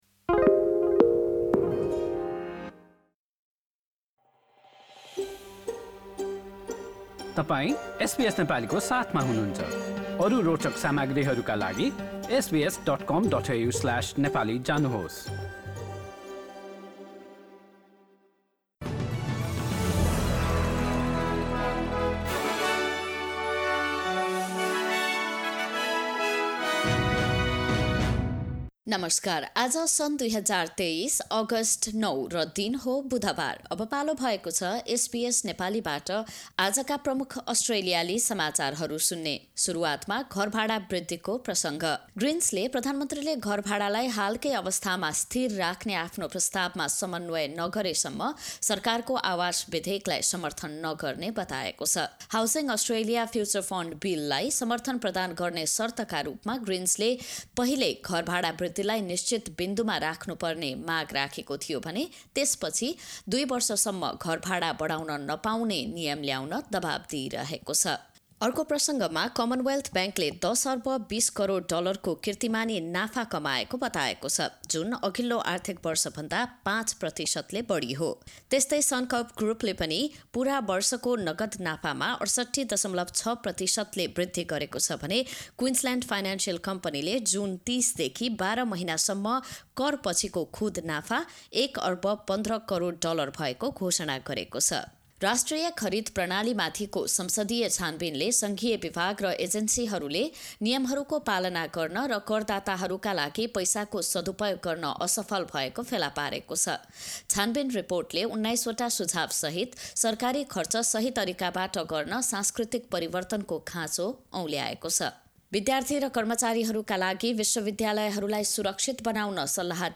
एसबीएस नेपाली प्रमुख अस्ट्रेलियाली समाचार: बुधवार, ९ अगस्ट २०२३